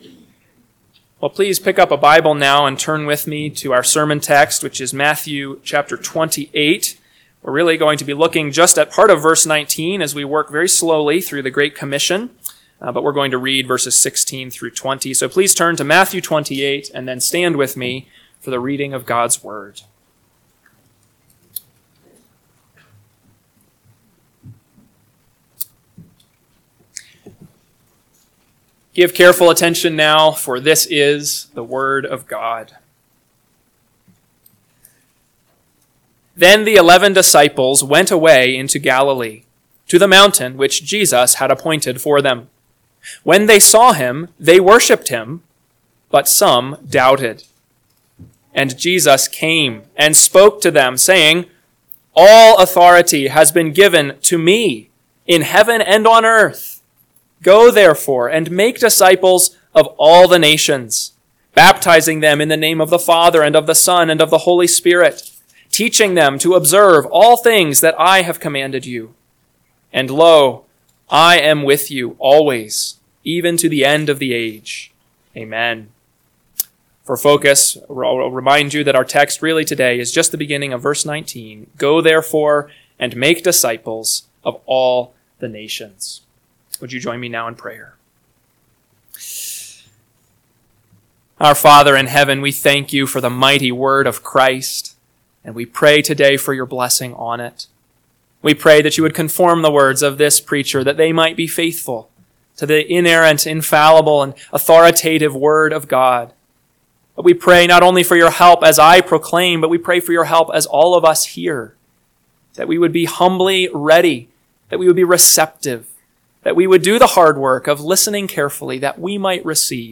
AM Sermon – 6/8/2025 – Matthew 28:19a – Northwoods Sermons